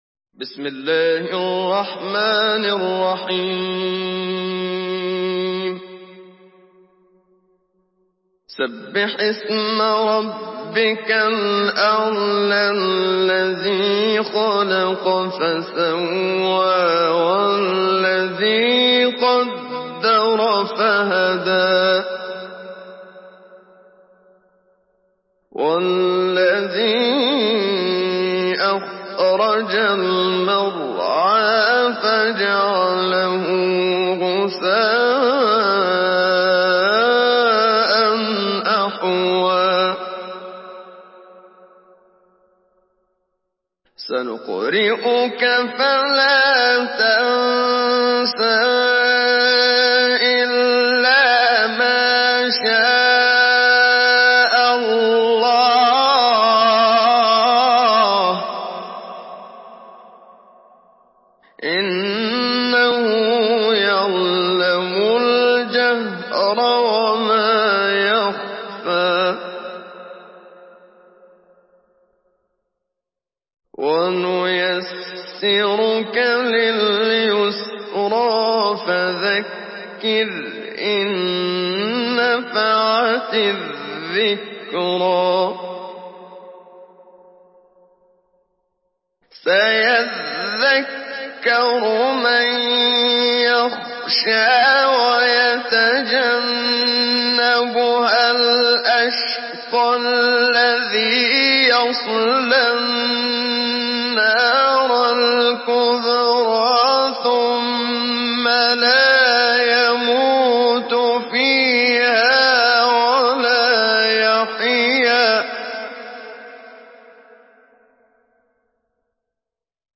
Surah আল-আ‘লা MP3 by Muhammad Siddiq Minshawi Mujawwad in Hafs An Asim narration.